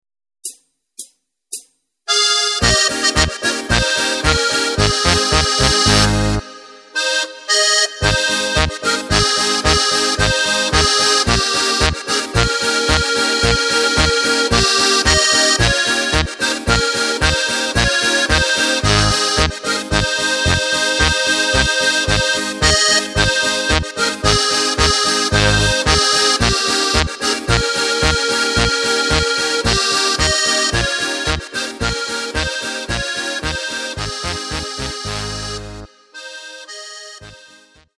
Takt:          2/4
Tempo:         111.00
Tonart:            Ab
Polka für Steirische Harmonika!